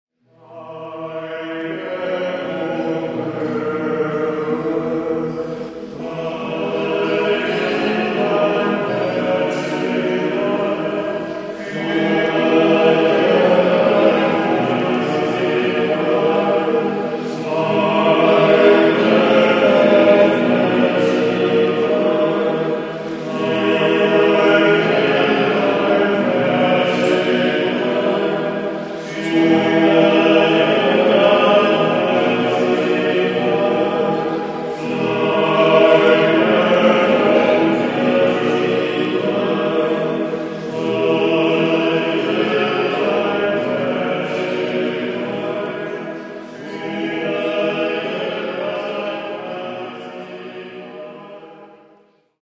(Kanon)